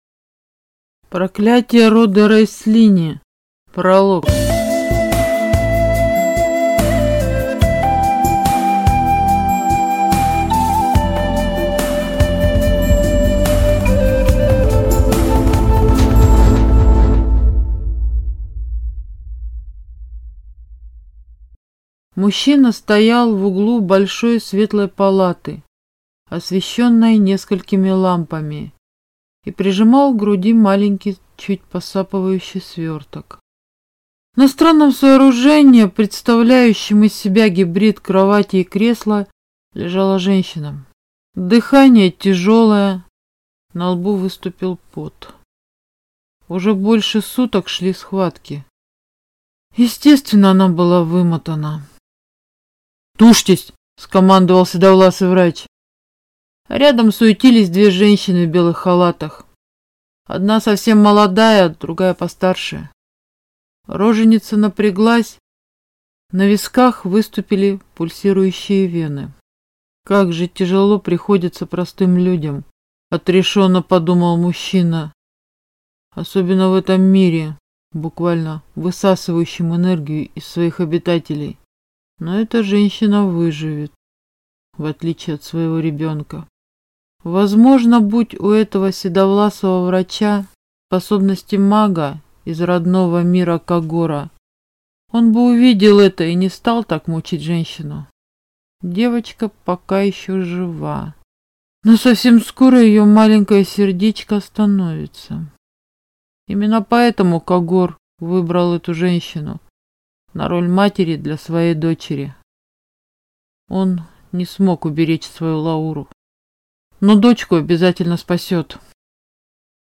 Аудиокнига Проклятие рода Рэслини | Библиотека аудиокниг